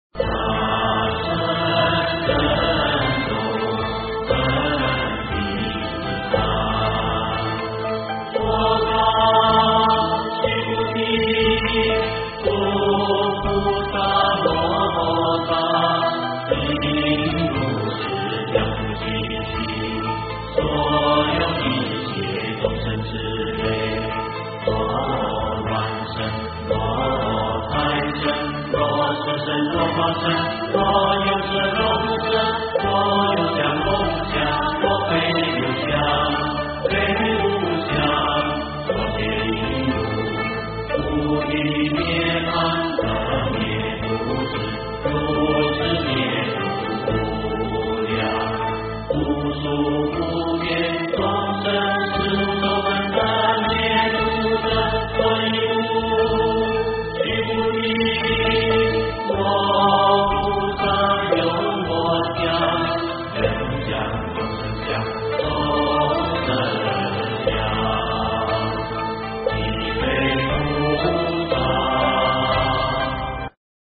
金刚经-大乘正宗分第三 诵经 金刚经-大乘正宗分第三--未知 点我： 标签: 佛音 诵经 佛教音乐 返回列表 上一篇： 梵网经-十长养心 下一篇： 金刚经-妙行无住分第四 相关文章 菩提本无树 菩提本无树--群星...